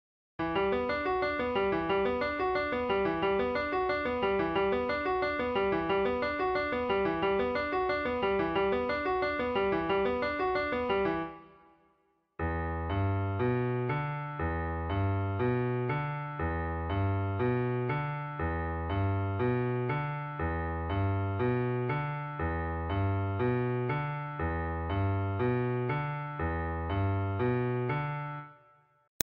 Piano Synthesia Tutorial